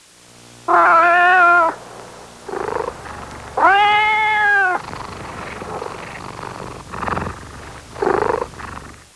Tiger cub growl
Tiger cub chillin’.